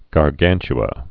(gär-gănch-ə)